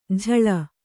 ♪ jhaḷa